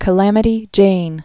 (kə-lămĭ-tē jān)